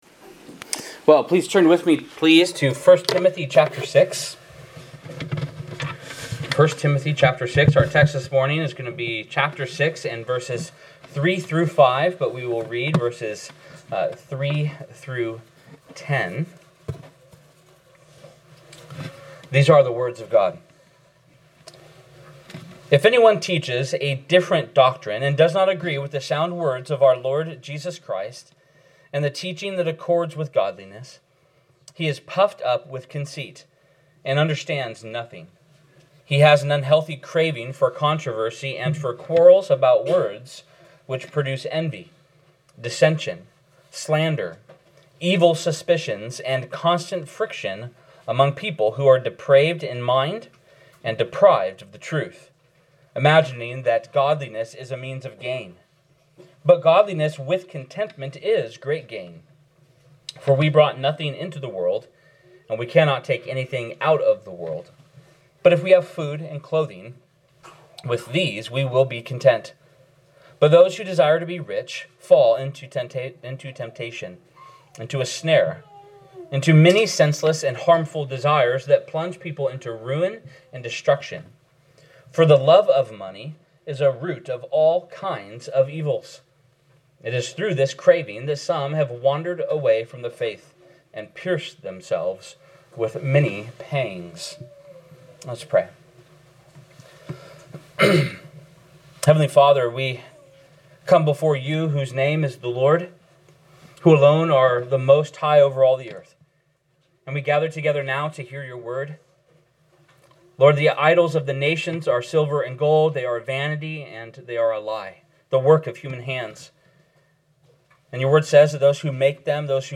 by Church Admin | Nov 9, 2023 | Sermons